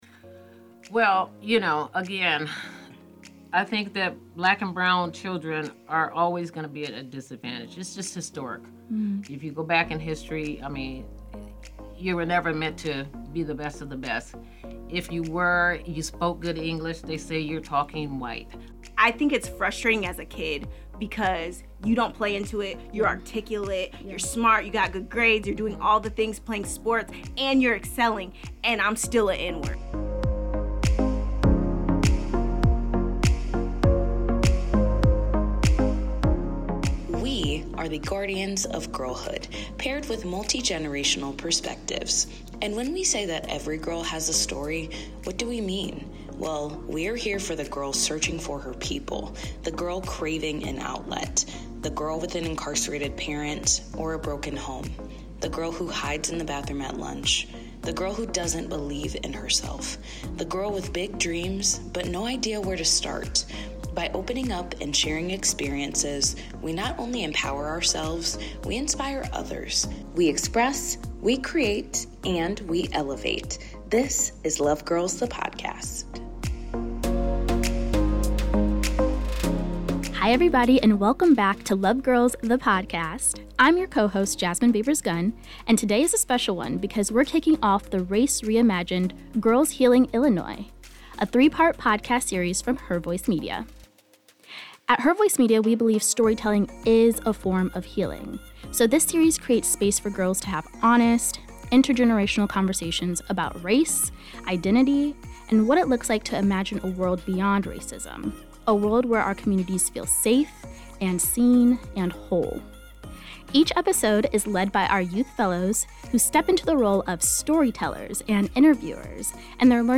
LOVE Girls contributors sit down with young women from the Quad Cities and beyond for intimate conversations about young adulthood, touching on difficult topics such as mental health while also having some fun along the way.